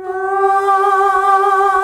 AAAAH   G.wav